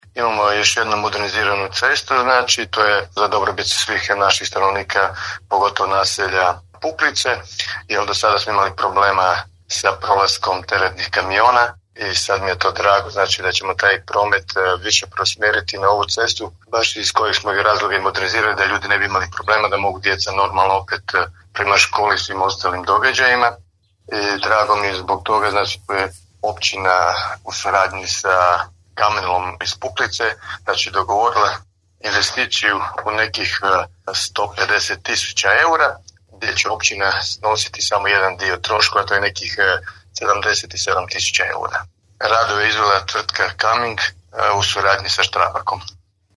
U Općini Đulovac modernizirana je cesta prema Puklici u dužini od jednog kilometra, širine šest metara. O važnosti ove prometnice za stanovnike Puklice i Općine Đulovac, samoj investiciji i modelu zatvaranja financijske konstrukcije za realizaciju posla, informacijsku podršku pruža načelnik Općine Đulovac Anđelko Kolić